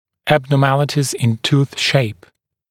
[ˌæbnɔː’mælɪtɪz ɪn tuːθ ʃeɪp][ˌэбно:’мэлитиз ин ту:с шэйп]аномалии формы зубов